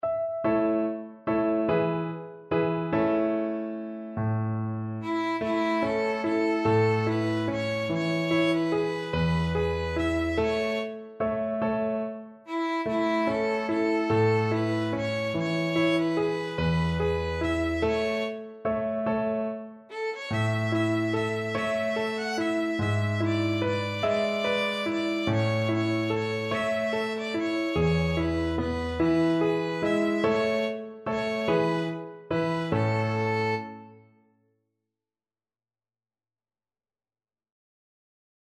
One in a bar =c.145
3/4 (View more 3/4 Music)